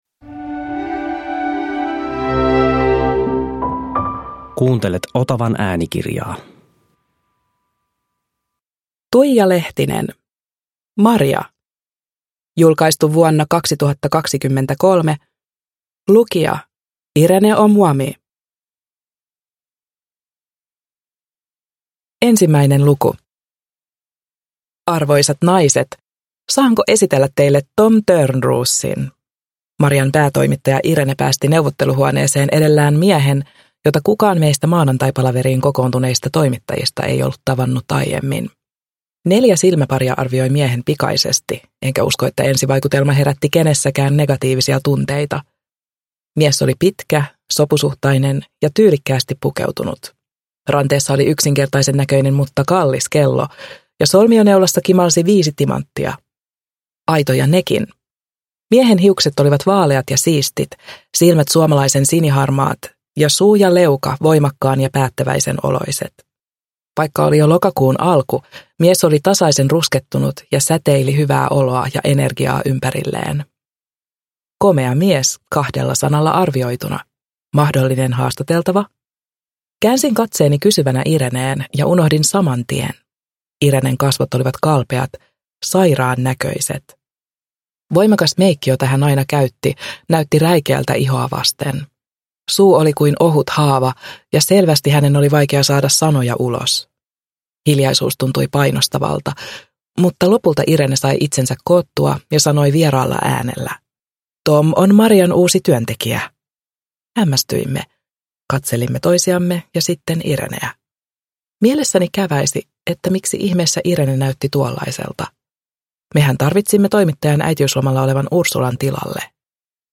Maria (ljudbok) av Tuija Lehtinen